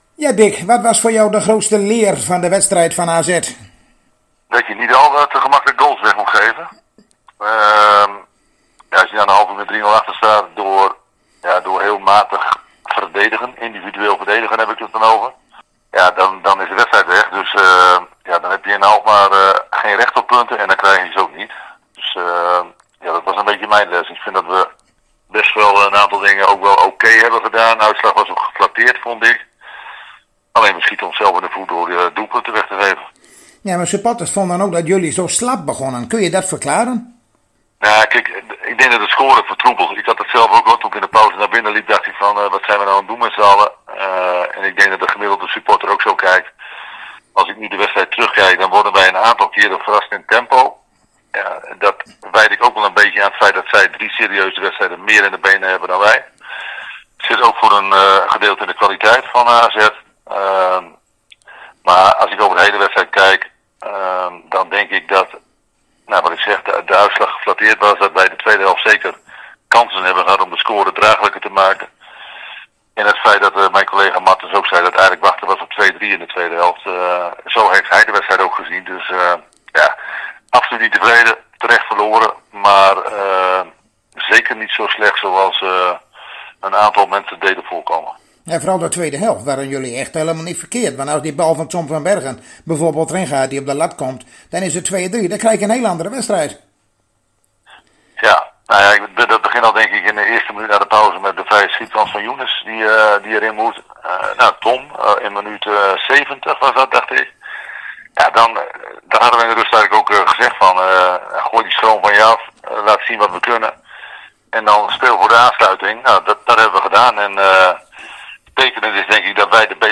Zojuist spraken wij weer met trainer Dick Lukkien van FC Groningen over de wedstrijd van morgen tegen SC Heerenveen en we plaatsten een paar foto's van de training van vandaag.